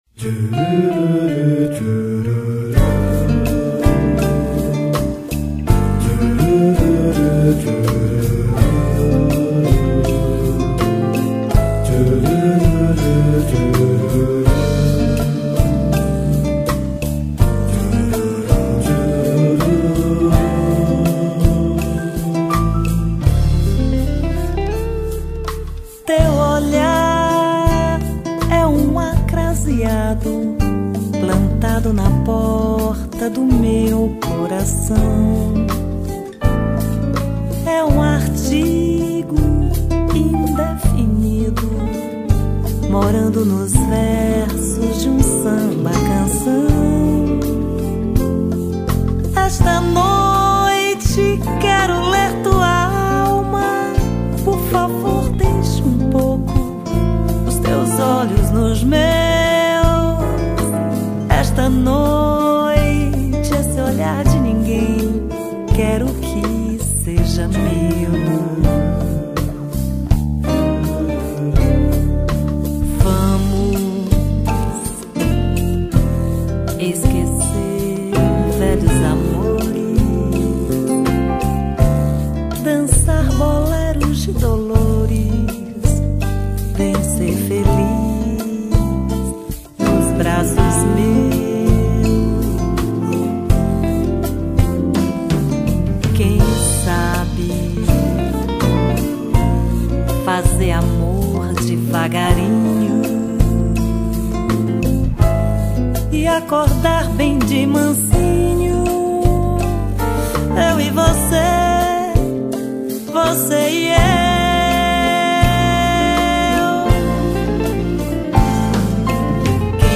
1836   04:45:00   Faixa:     Bossa nova